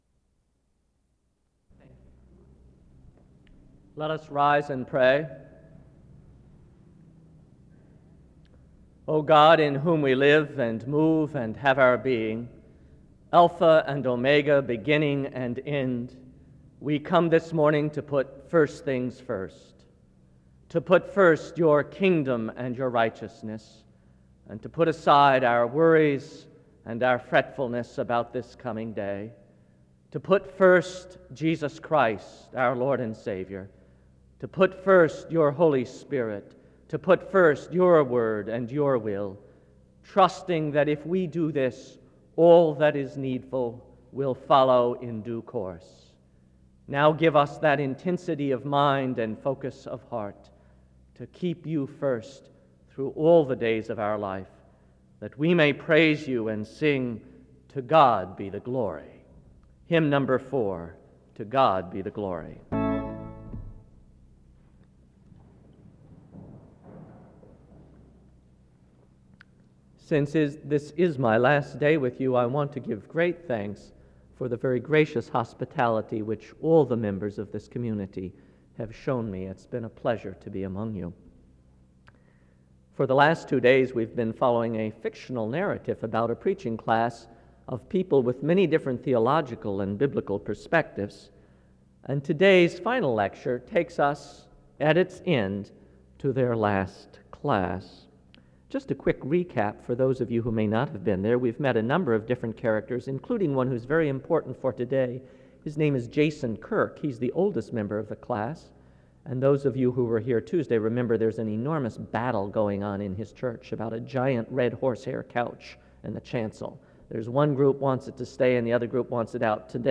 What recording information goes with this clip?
CO. The service opens with a prayer from 0:00-1:00.